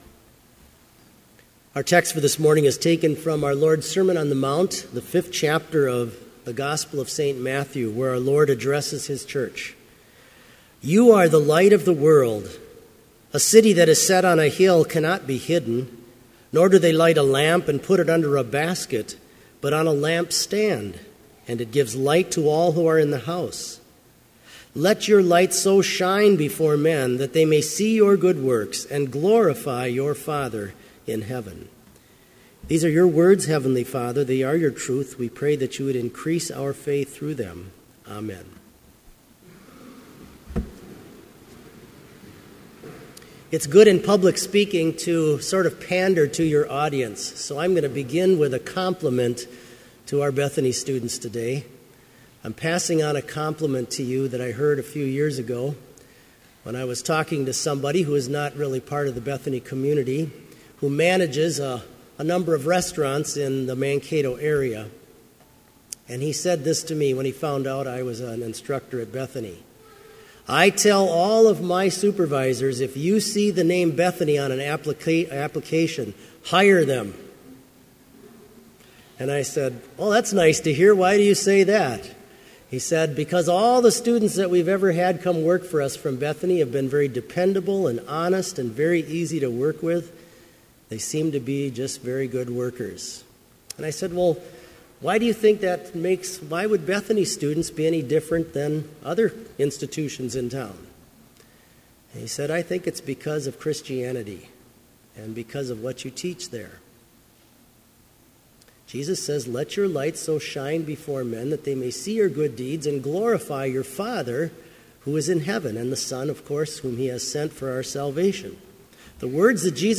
Complete service audio for Chapel - January 30, 2015